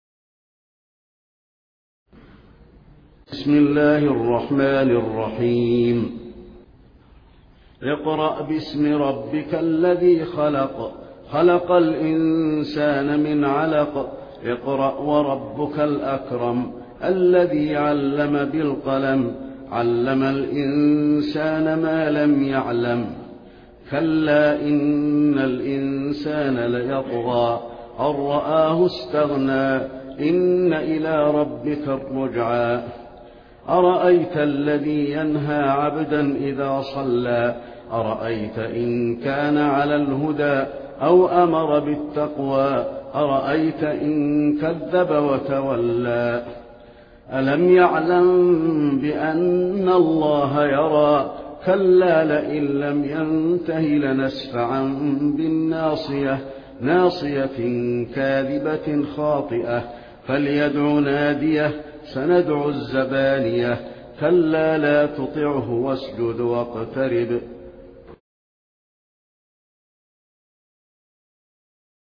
Taraweeh Prayer 1427